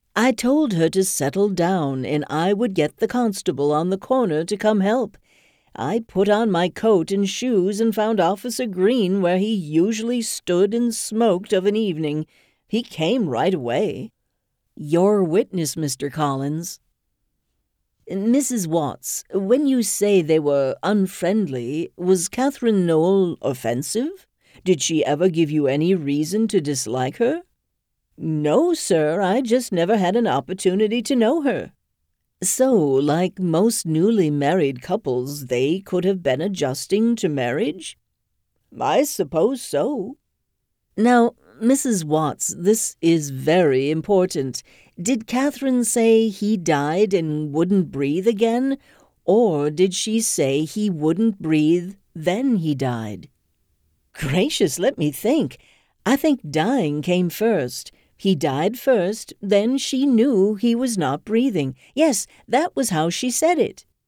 Audiobook Narration Demo
Audiobook-Demo-Courtroom-Scene-1.mp3